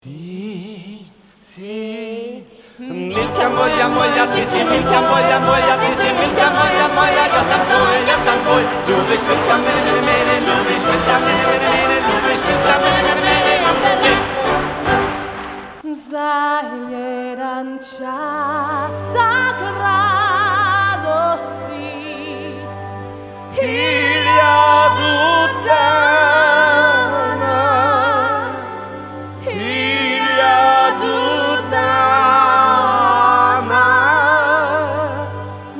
key: G-Major